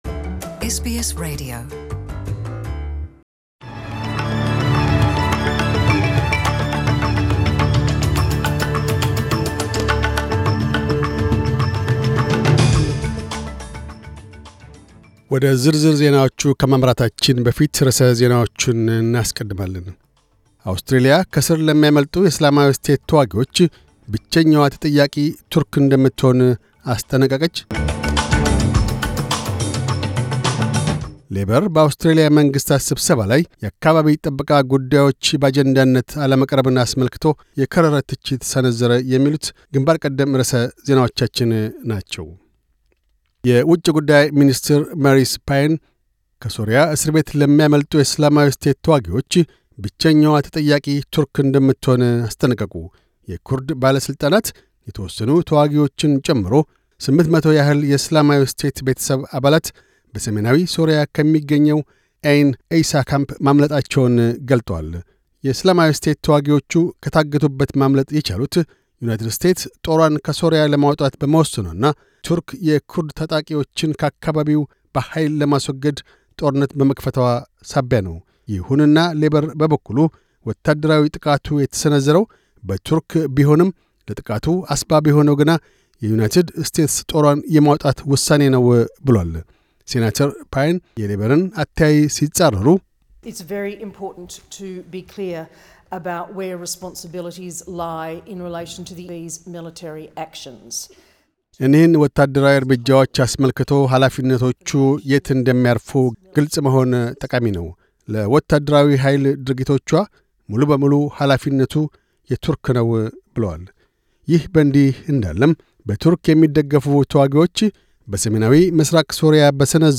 News Bulletin 1410